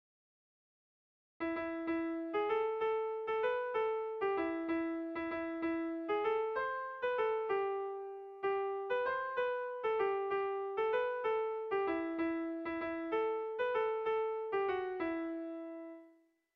Air de bertsos - Voir fiche   Pour savoir plus sur cette section
Lauko handia (hg) / Bi puntuko handia (ip)
AB